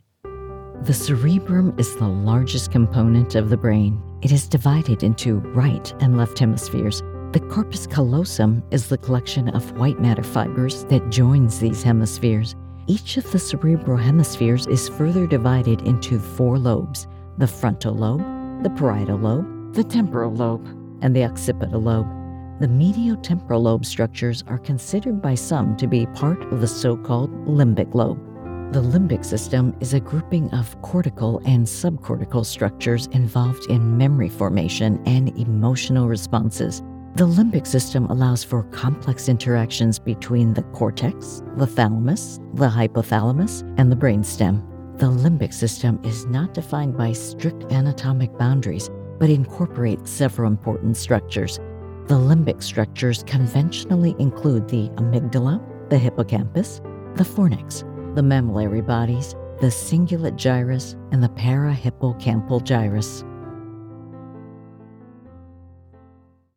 Medical: Brain Anatomy